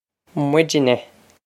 Muidne mwid-nah
mwid-nah